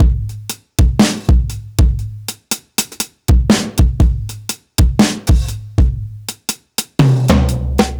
Unison Jazz - 3 - 120bpm.wav